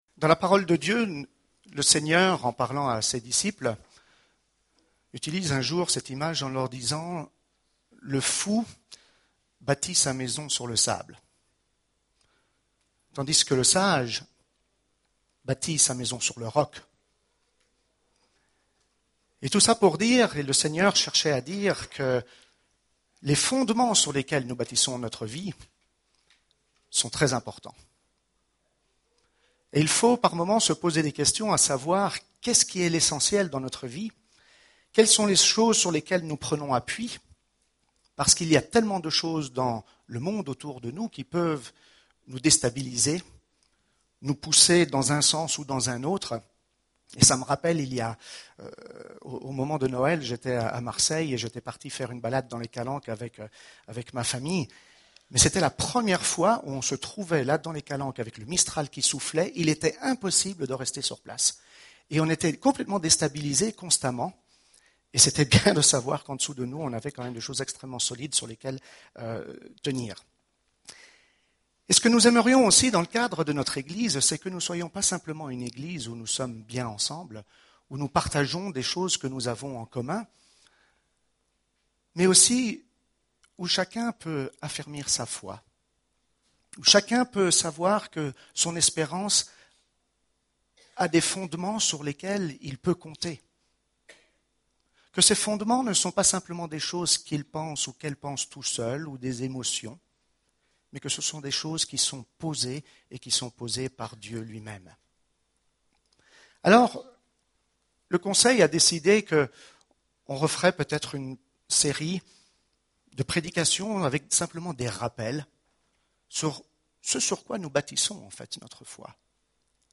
Preacher: Conseil d'anciens | Series:
Culte du 5 février